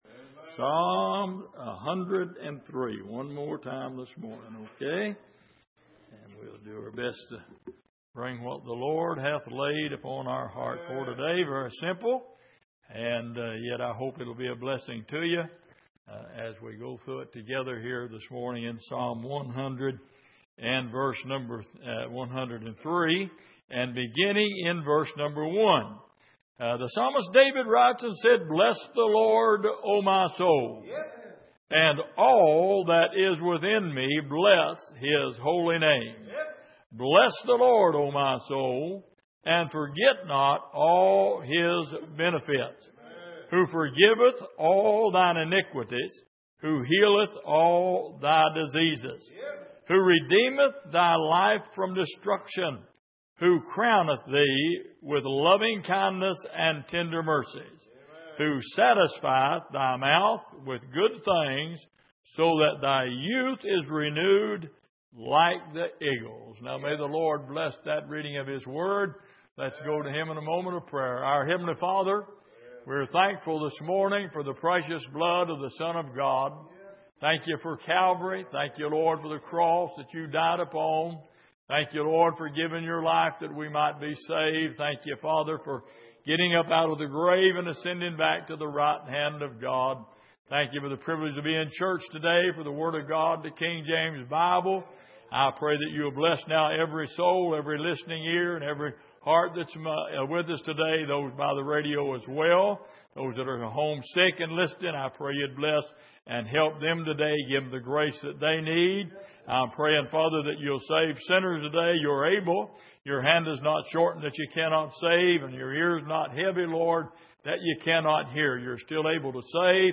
Passage: Psalm 103:1-5 Service: Sunday Morning